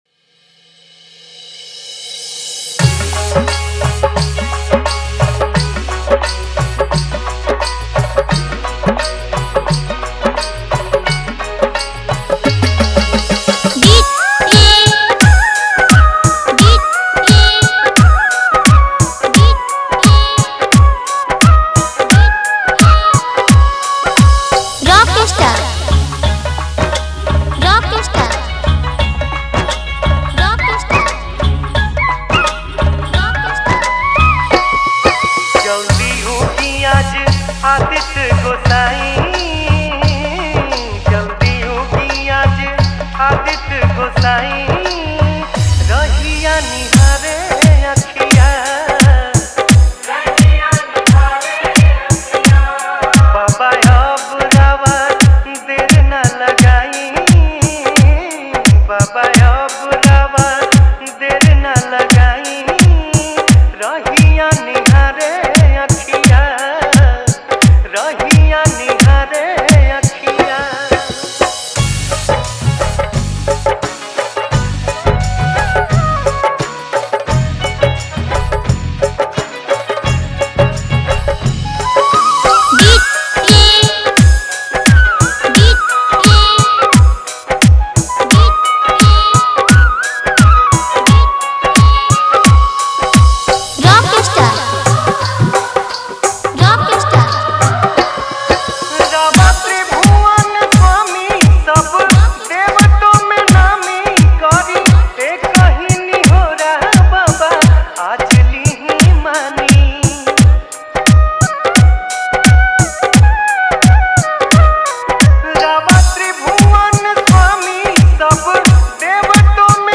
Category:  Chhath Puja Dj Songs 2022